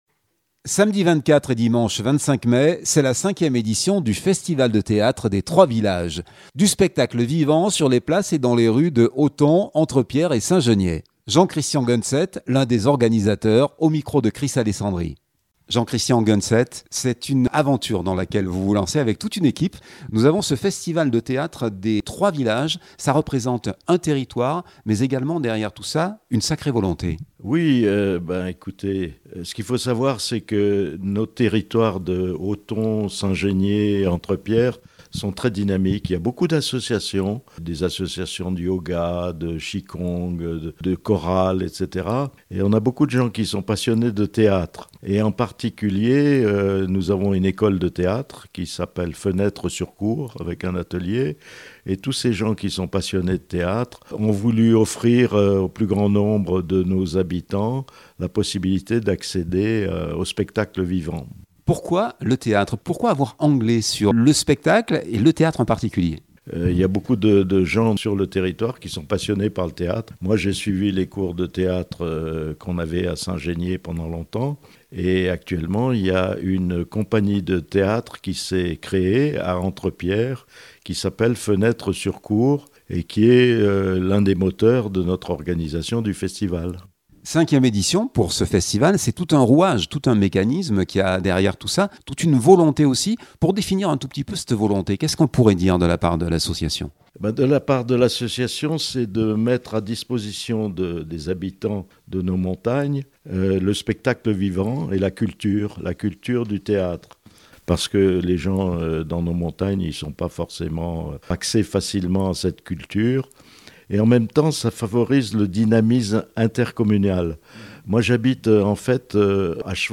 l’un des organisateurs au micro